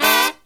FALL HIT09-L.wav